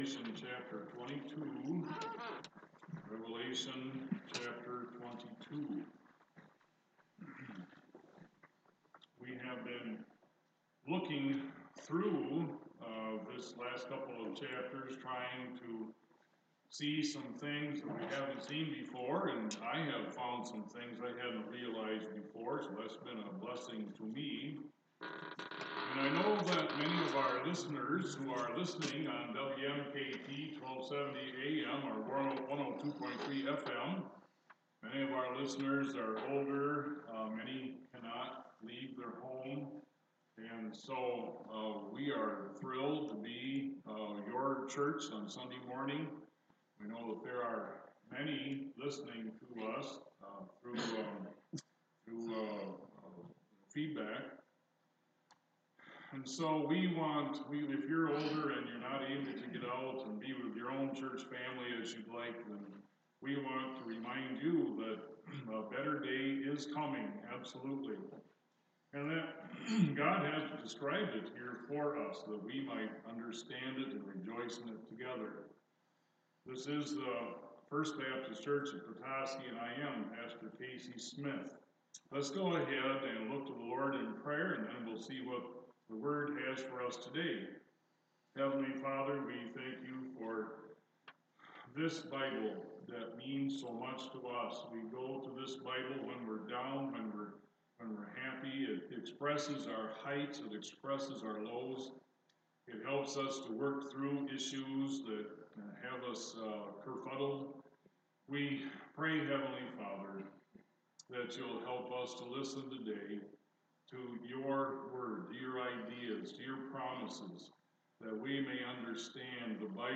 5-5-2019 Sunday Morning Message